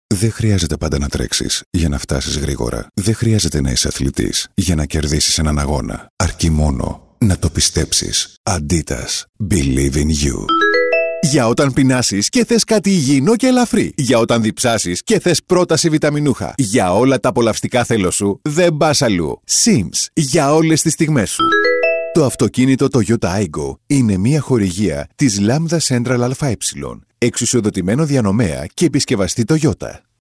Commerciale, Naturelle, Fiable, Chaude, Corporative
Corporate